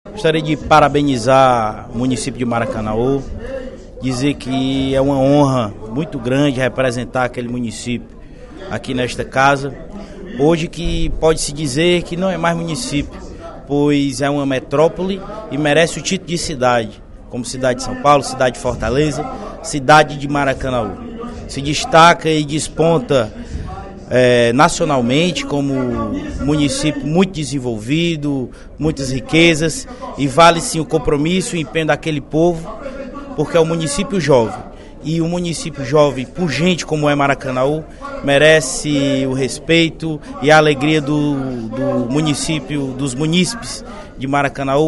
O aniversário de 29 anos de emancipação política de Maracanaú, celebrado hoje (06/03), foi o tema do pronunciamento do deputado Júlio César Filho (PTN) na sessão legislativa desta terça-feira.